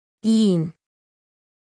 Phoneme_(Umshk)_(Iin)_(Female).mp3